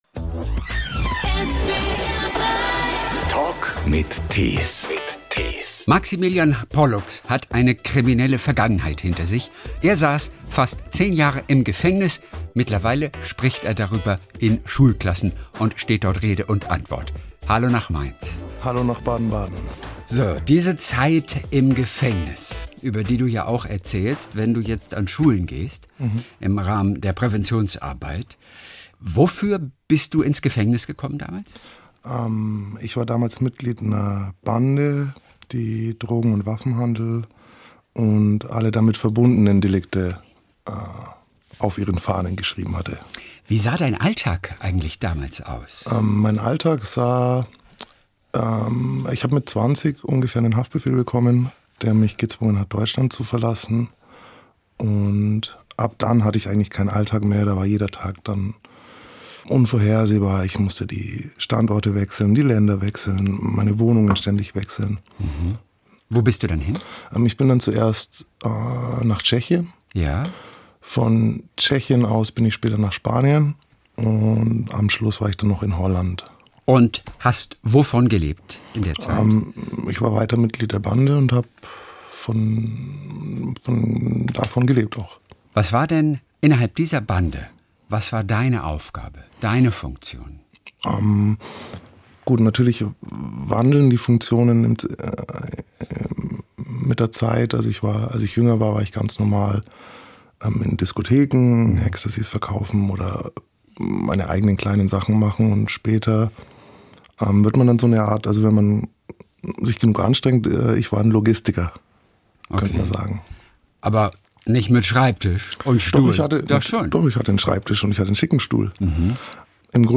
Der Talk in SWR3